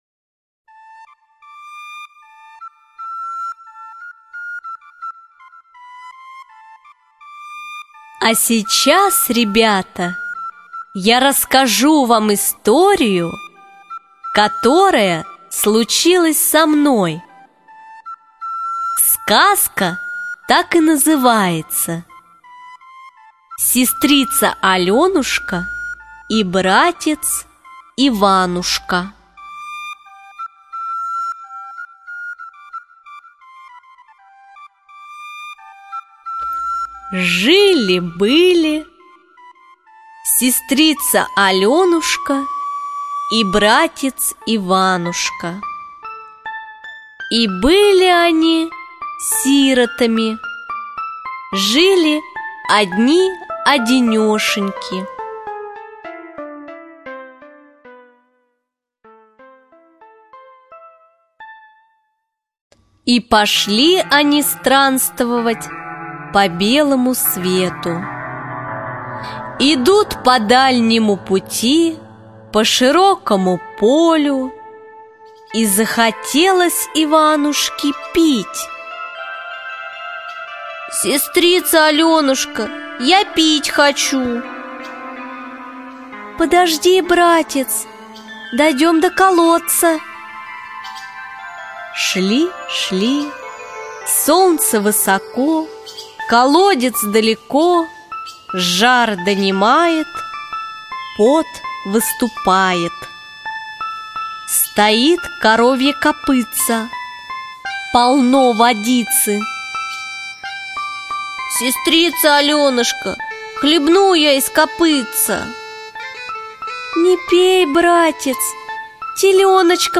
Аудиосказка Сестрица-Алёнушка и братец-Иванушка для детей любого возраста в формате mp3 — слушать или скачать бесплатно и без регистрации.